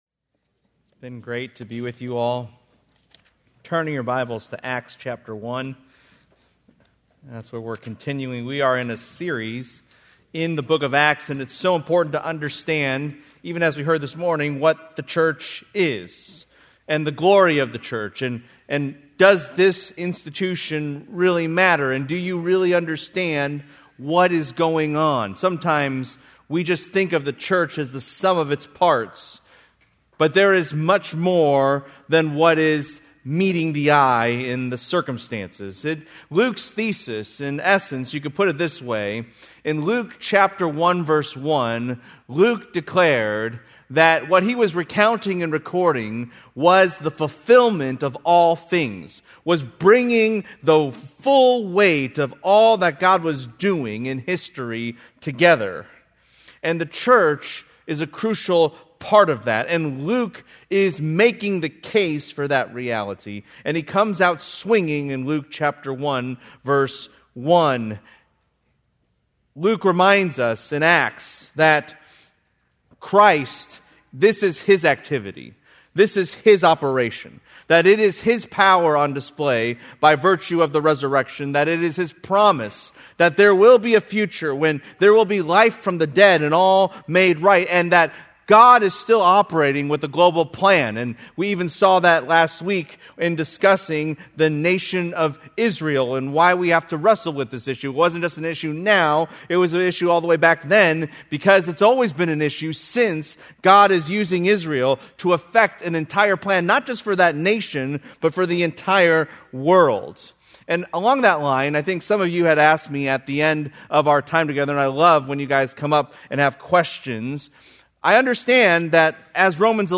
Due to technical difficulties, this sermon is incomplete.